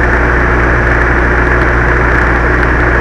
fan_loop.wav